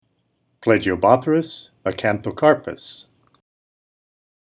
Pronunciation/Pronunciación:
Pla-gi-o-bó-thrys a-can-tho-cár-pus